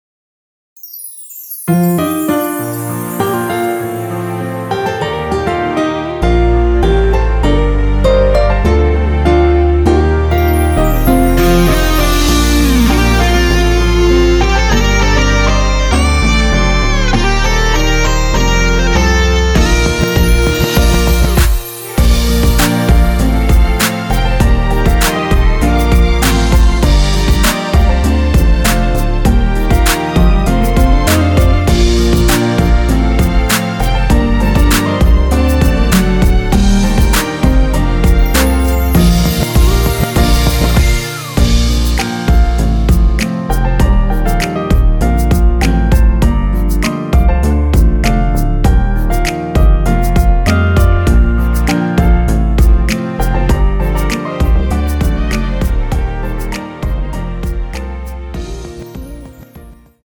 원키에서(-1)내린 MR입니다.
Bb
앞부분30초, 뒷부분30초씩 편집해서 올려 드리고 있습니다.
중간에 음이 끈어지고 다시 나오는 이유는